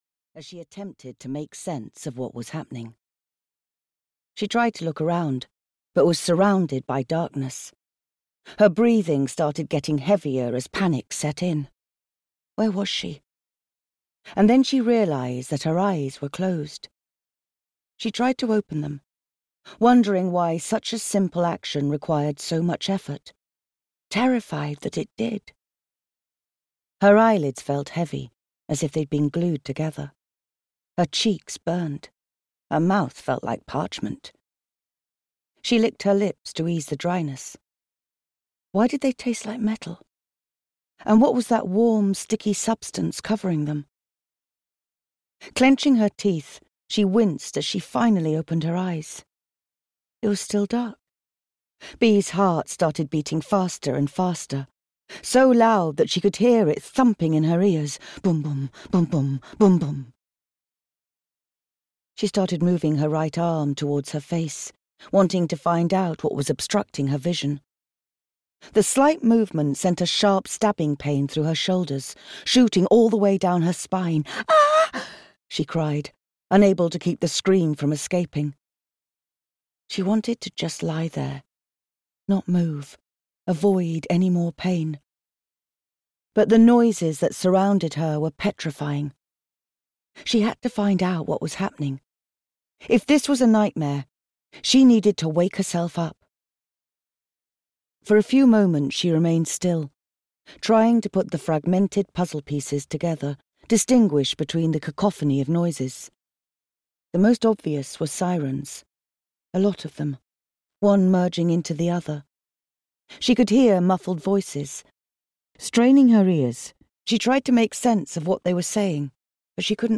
We All Fall Down (EN) audiokniha
Ukázka z knihy